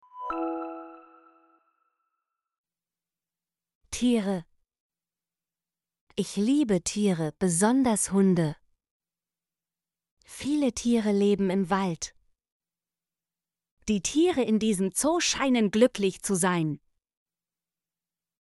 tiere - Example Sentences & Pronunciation, German Frequency List